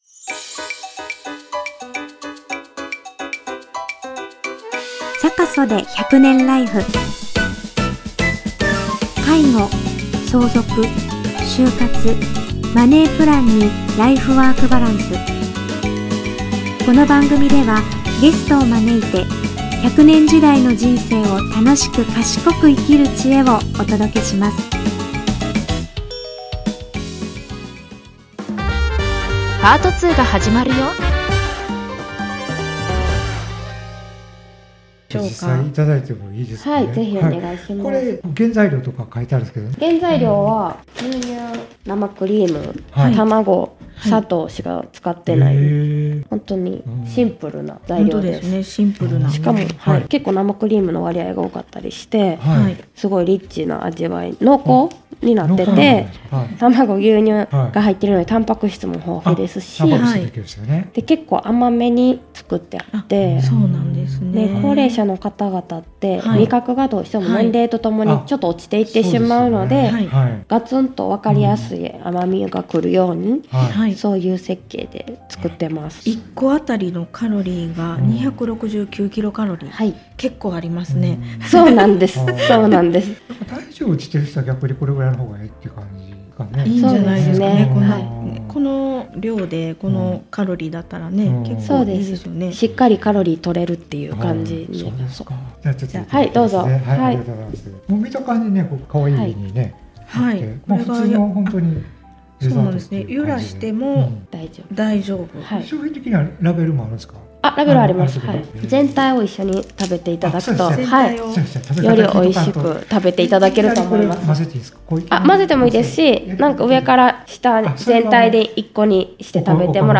ぎりぎりの柔らかさを保ったなめらかプリンで、食事量が少なくなった高齢者の方々にとって簡単にエネルギーを摂取でき、栄養面でも優れているとのこと！実際に試食しながら、お話を伺いました。